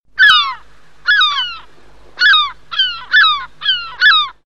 • Animal Ringtones